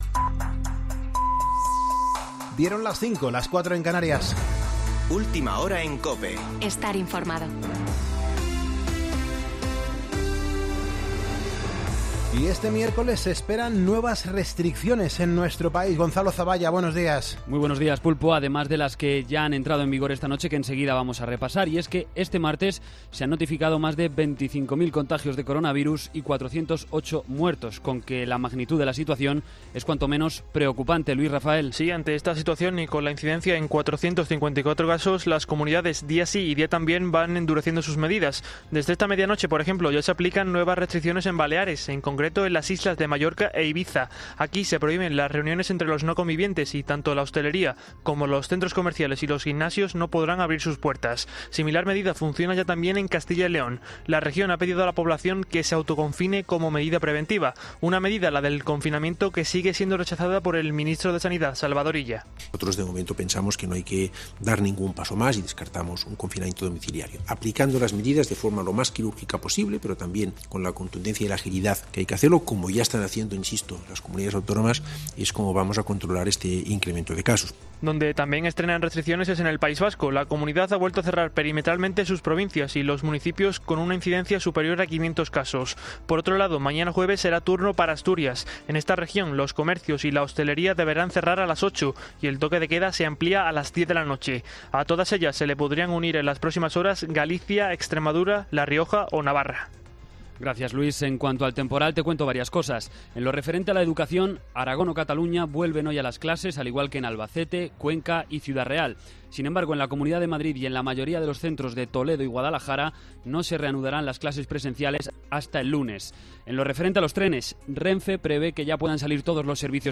Boletín de noticias COPE del 13 de enero de 2020 a las 05.00 horas